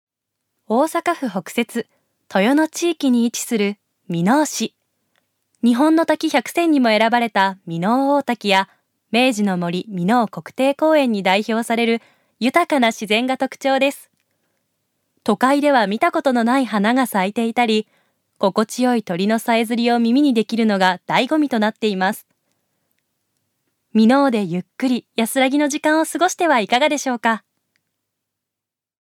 預かり：女性
ナレーション１